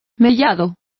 Complete with pronunciation of the translation of ragged.